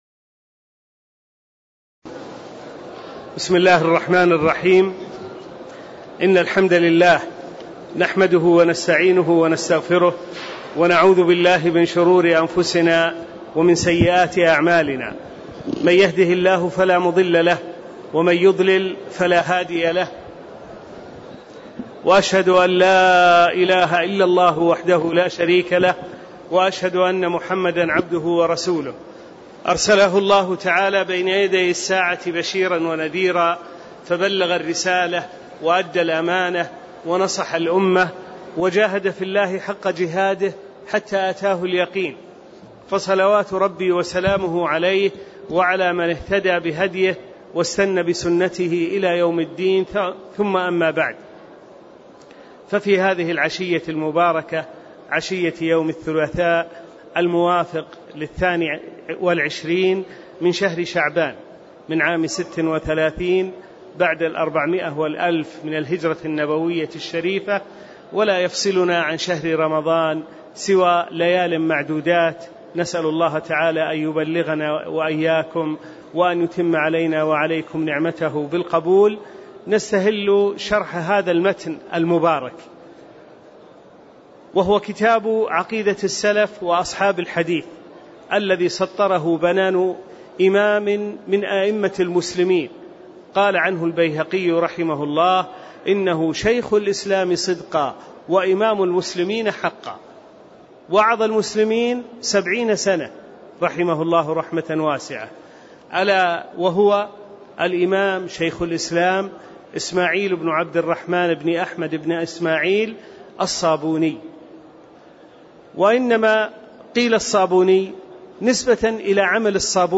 تاريخ النشر ٢٢ شعبان ١٤٣٦ هـ المكان: المسجد النبوي الشيخ